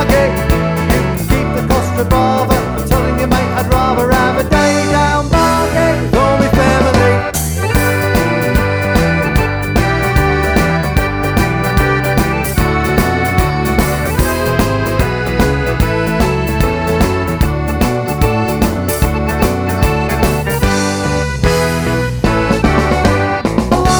no Backing Vocals Rock 'n' Roll 2:17 Buy £1.50